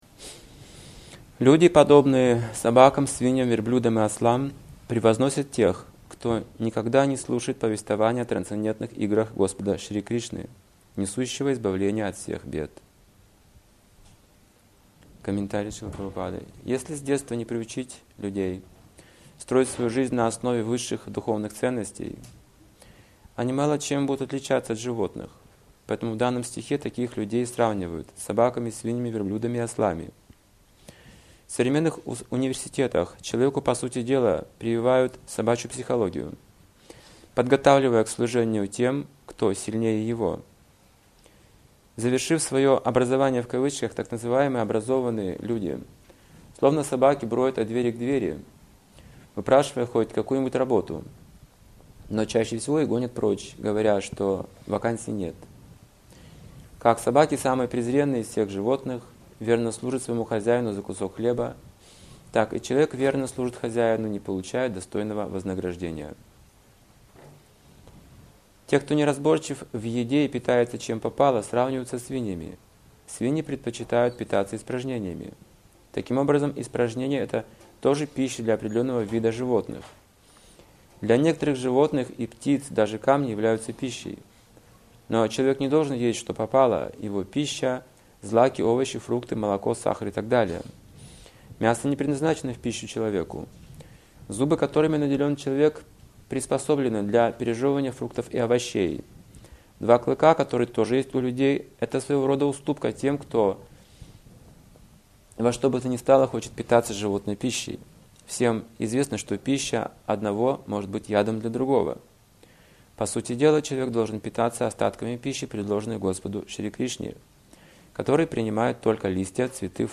Темы, затронутые в лекции: Человекоподобные собаки, свиньи, верблюды и ослы Бхава рождения Подлинная, отражённая и извращённая бхакти Ответственность за свою духовную жизнь. Отношения гуру и ученика Воспитание. Развитие трансцендентного сознания Почему Душа уходит от Бога Пока в человеке не развита духовная Любовь Освободить ум Чистое преданное служение. Саньяси и грихастка